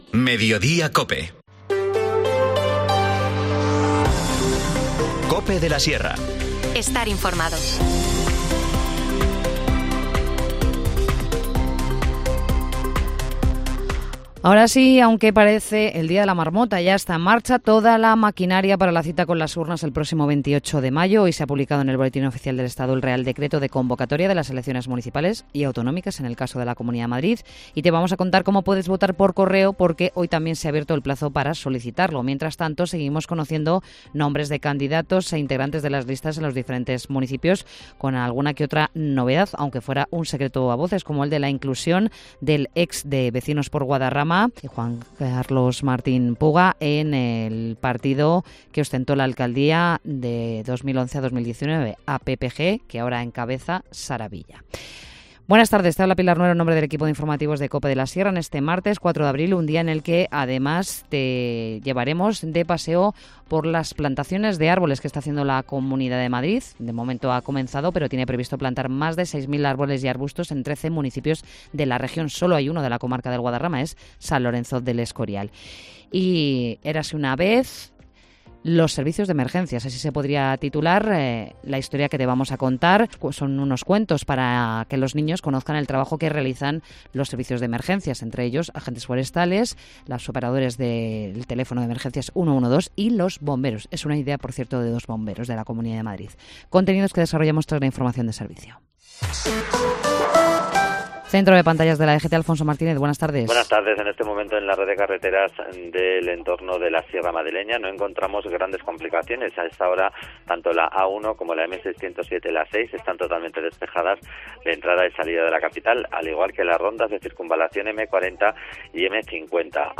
Informativo Mediodía 4 abril